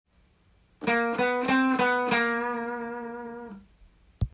④のフレーズもコードトーンで着地するフレーズですが
７度が終着音になるので、少しテンションぽい感じでカッコいいですね